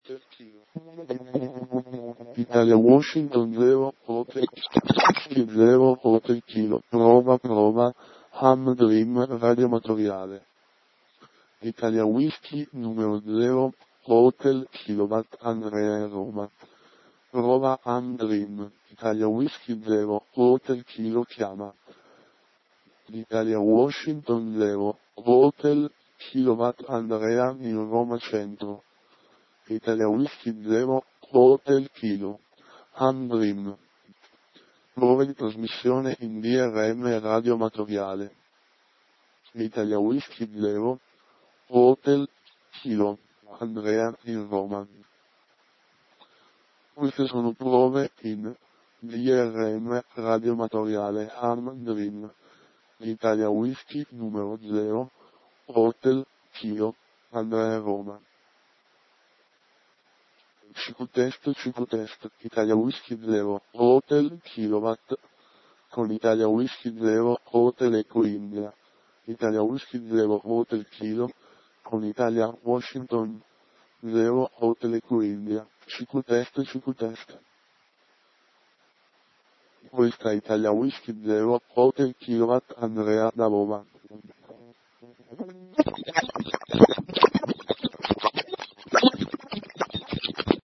(Drm Mode b - BandWidth 2,25 khz Codfm 16 QAM)